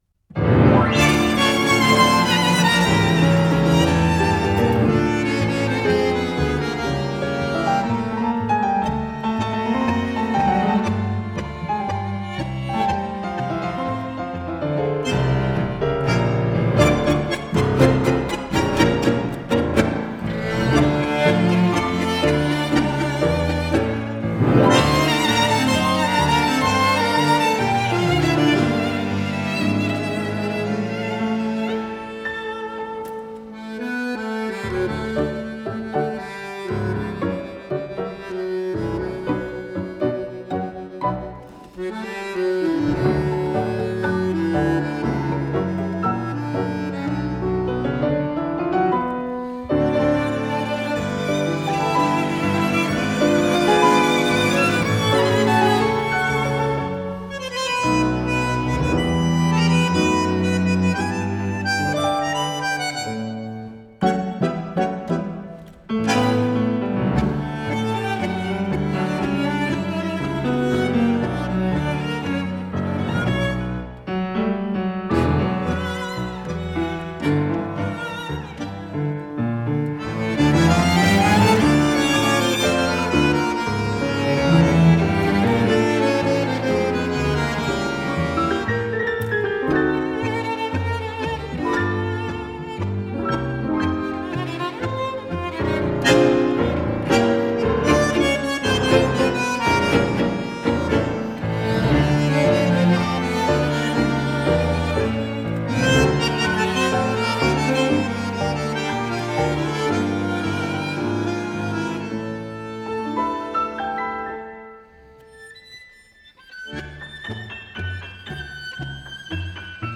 chamber-sized ensemble.
Genre: Tango